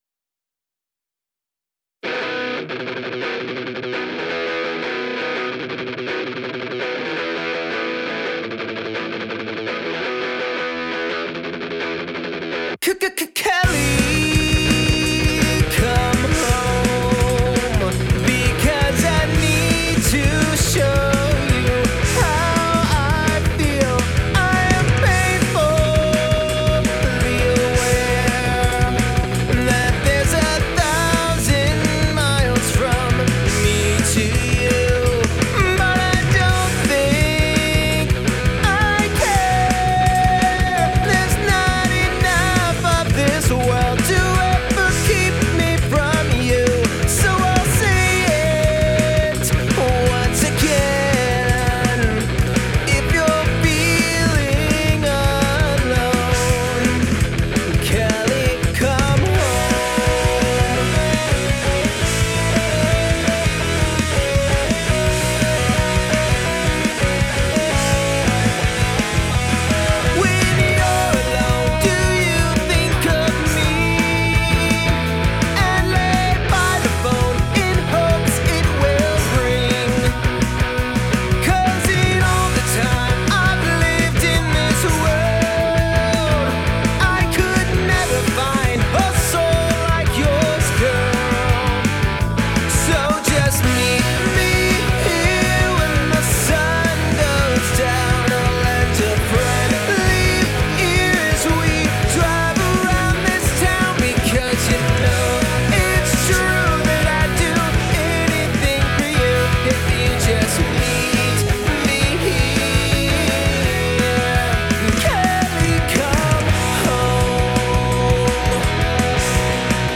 Performer Vocals, Guitar, Bass, Drums